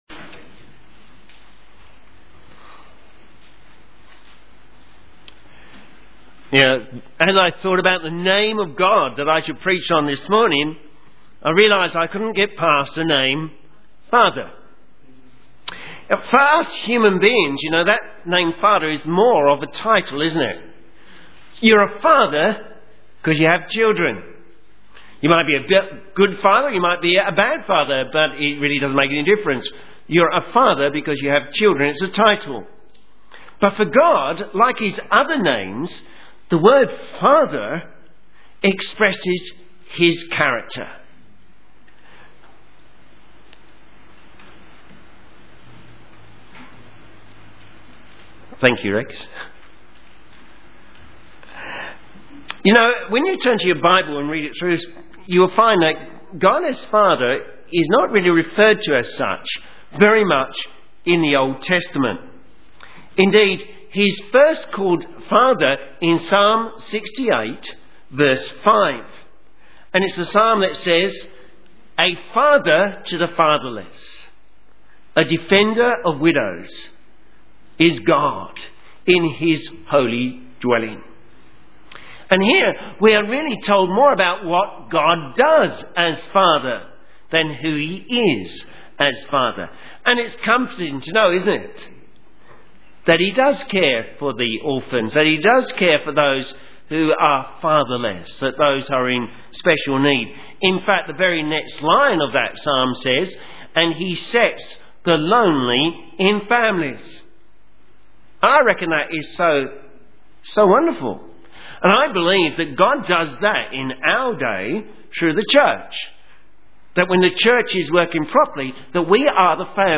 Sermon
Names of God: Father John 14:15-31 Synopsis A Father's Day sermon which concentrated on God as Father.